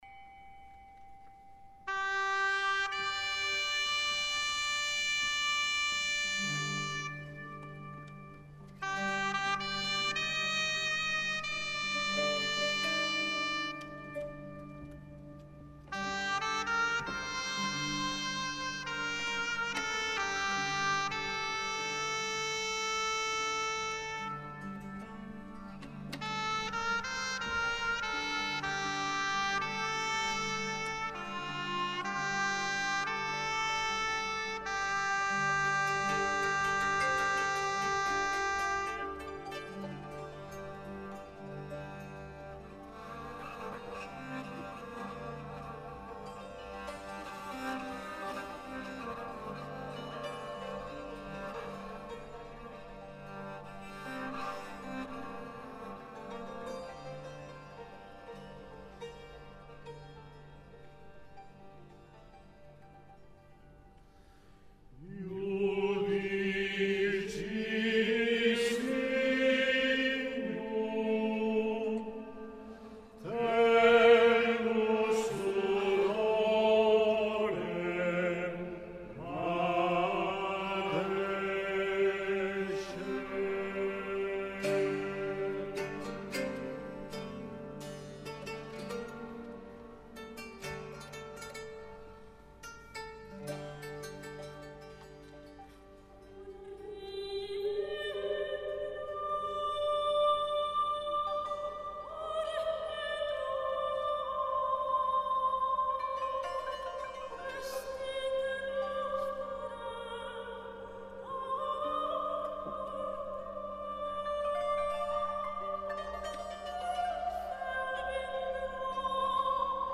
El text del Cant és en català i la melodia gregoriana està considerada la més antiga d’Europa.